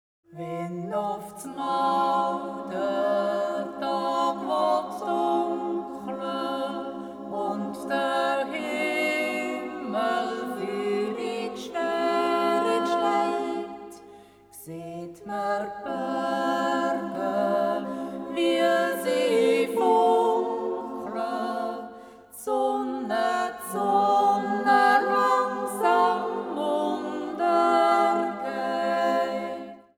Beschreibung:Volksmusik; Jodel; Jodellied
Besetzung:Frauenjodelchor